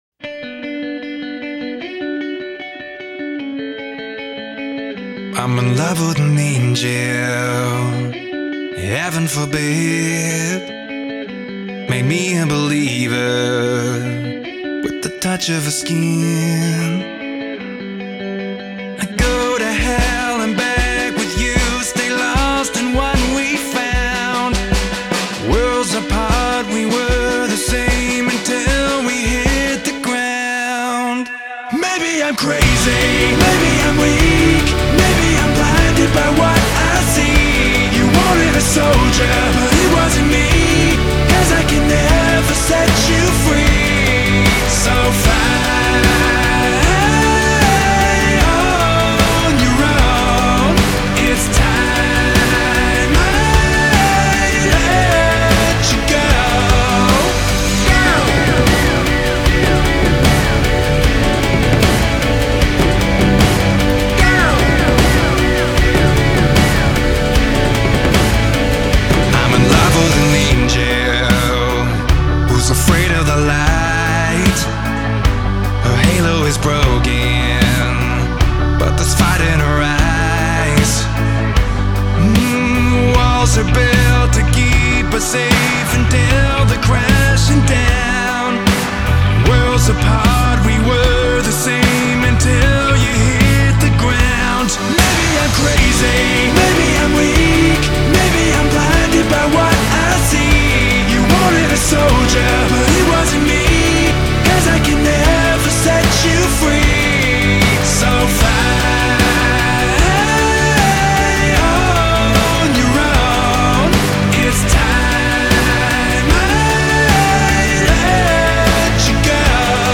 Rock [96]